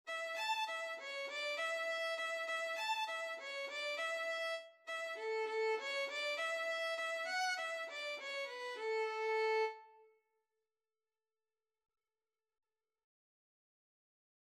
Traditional Music of unknown author.
2/4 (View more 2/4 Music)
Quick
A major (Sounding Pitch) (View more A major Music for Violin )
Violin  (View more Beginners Violin Music)
World (View more World Violin Music)